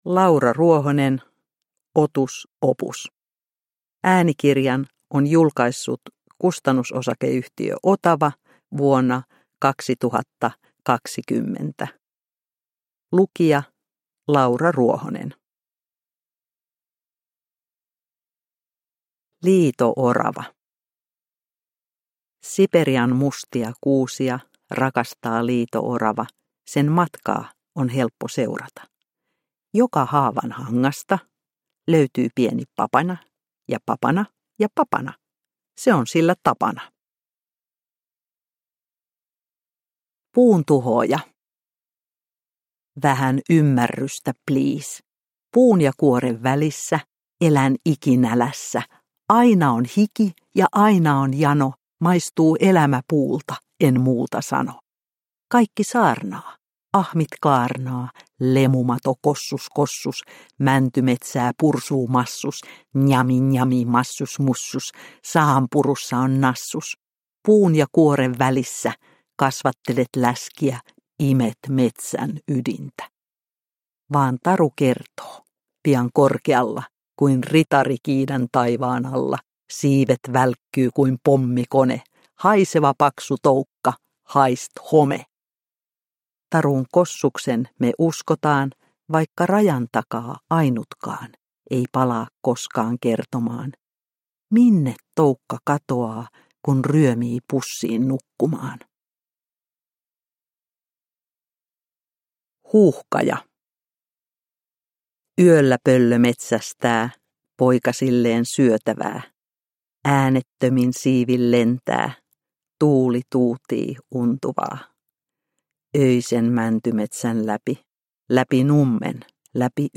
Otus opus – Ljudbok – Laddas ner